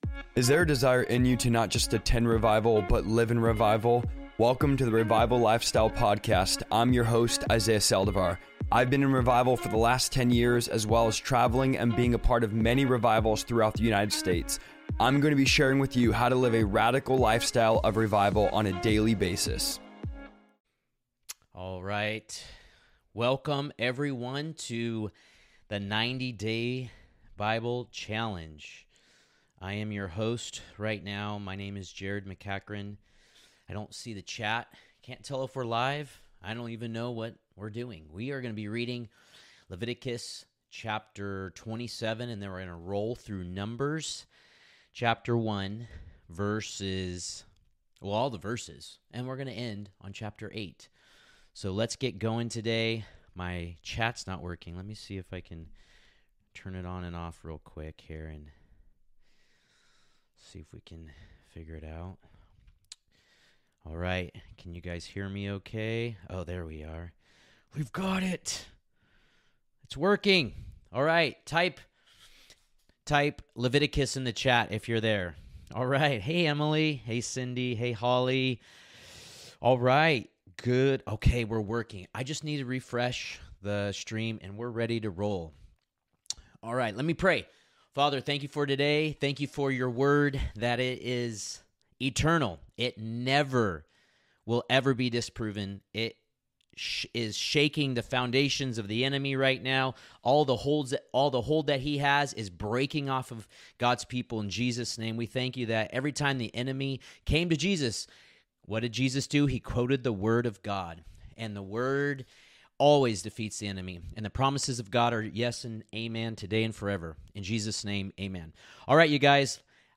I am going to be LIVE everyday at 2 PM for 90 days straight reading through the entire Bible!